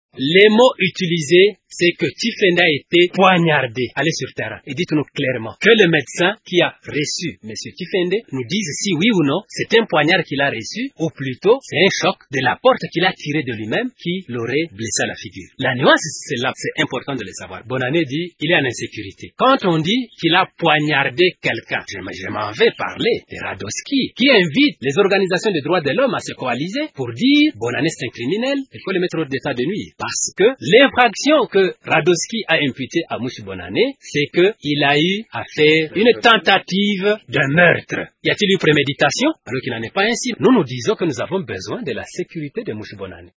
Alphonse Munarire, député national lui aussi et membre du comité provincial de l’UPRDI a réfuté cette version.
Il l’a dit dans cet extrait recueilli par Radio Okapi: